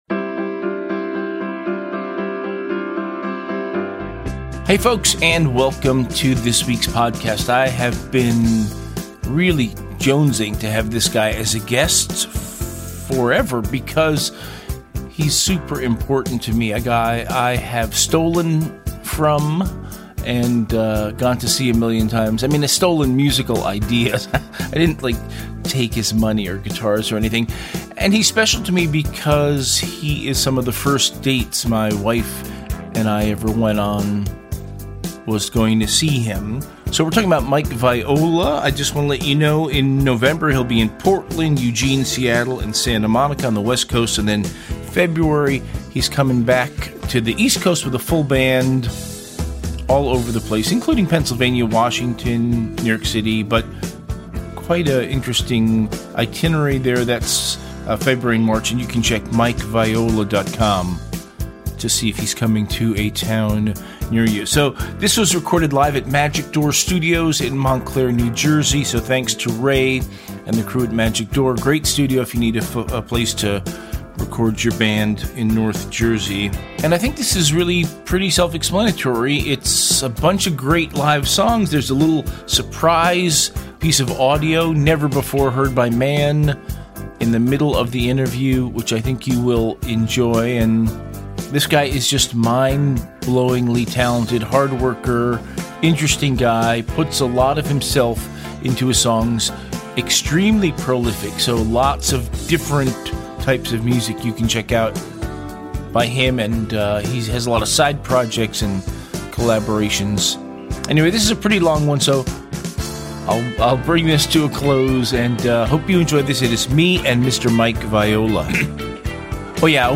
Guest: Mike Viola, Live Performance And Interview from Oct 24, 2025